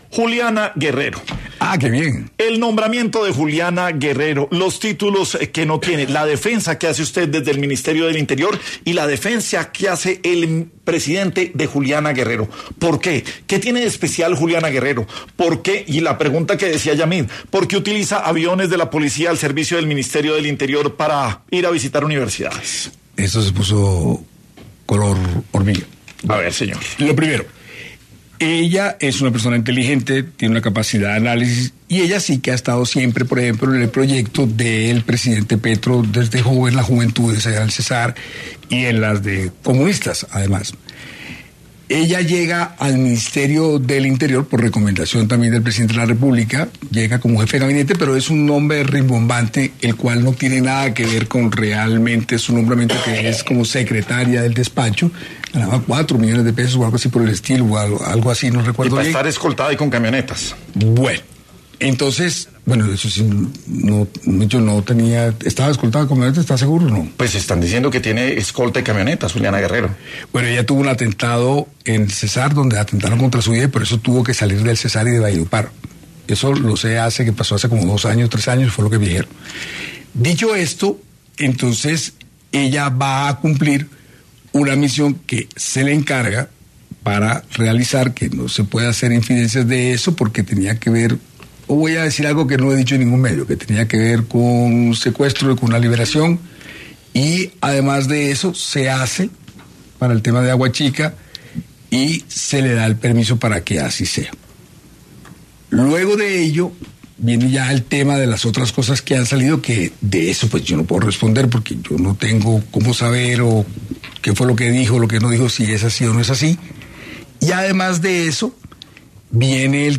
El ministro del Interior, Armando Benedetti, pasó por ‘Sin Anestesia’, de ‘La Luciérnaga’, para abordar todo lo referente a la polémica de Juliana Guerrero.